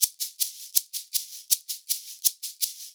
80 SHAK 11.wav